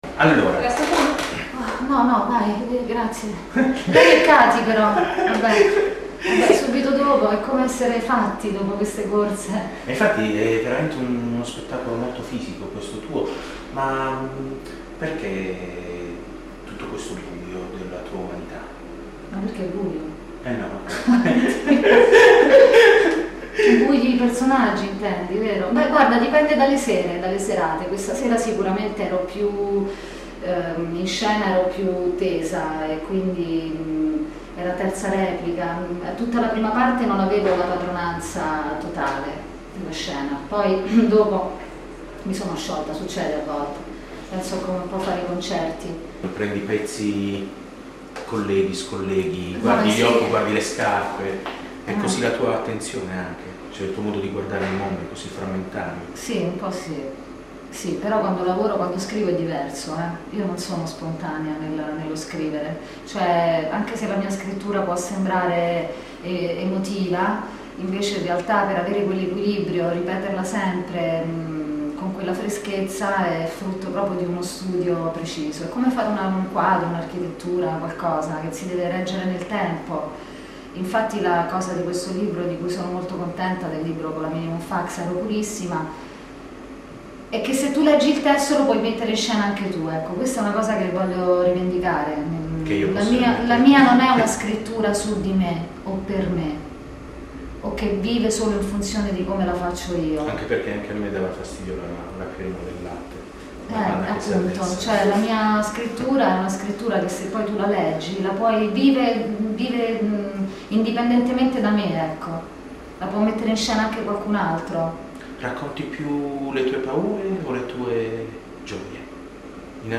Audiointervista